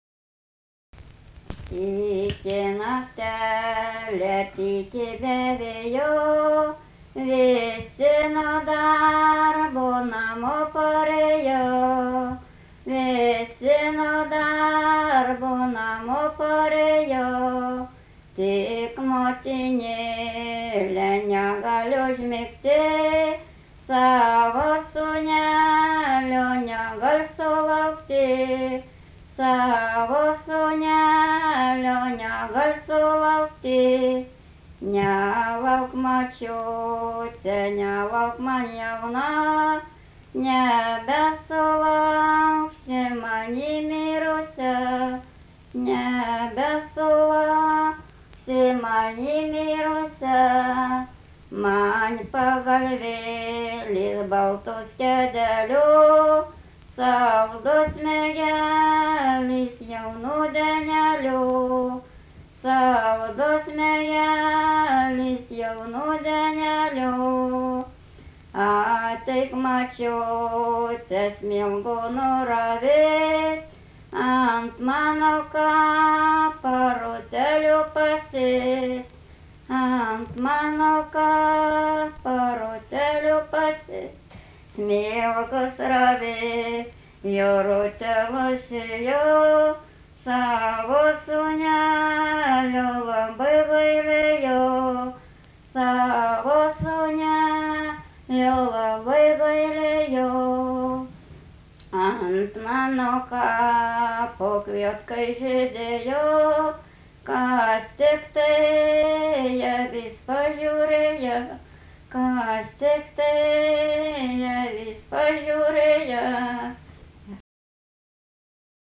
daina
Meilūnai
vokalinis